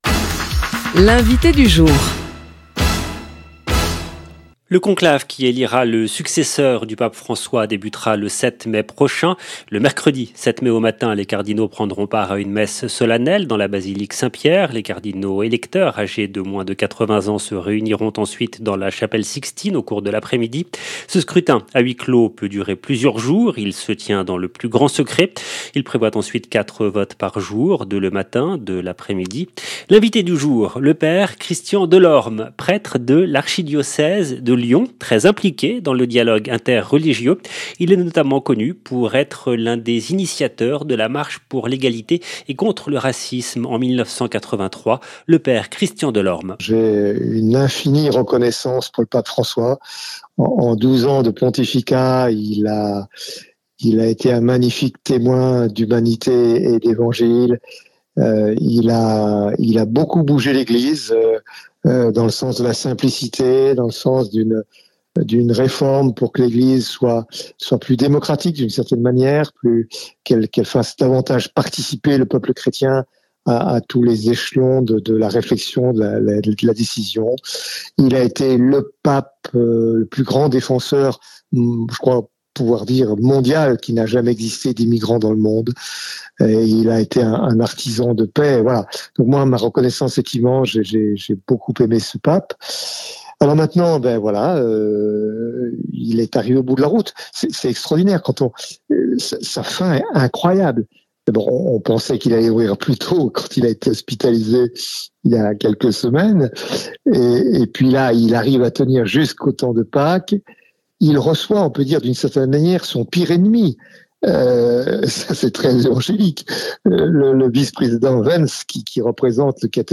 L’INVITÉ DU JOUR